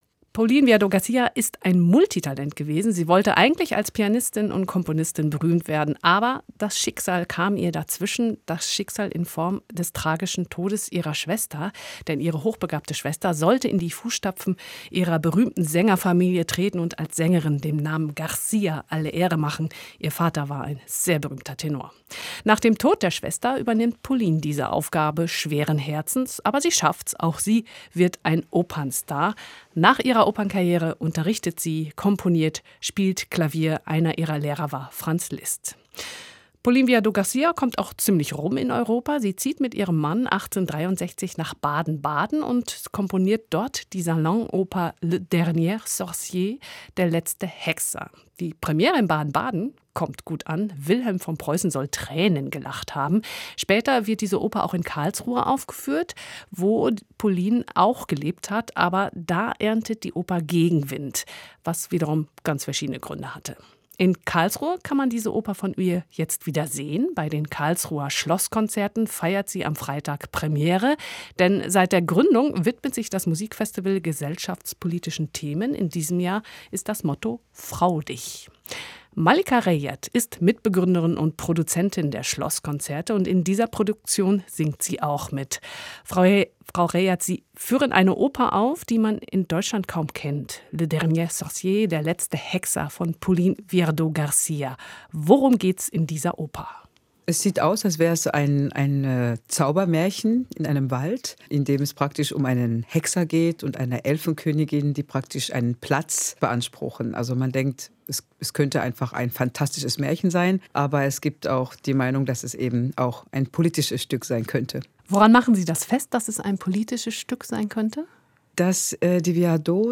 Musikgespräch
Interview mit